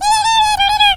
squeak_hurt_vo_01.ogg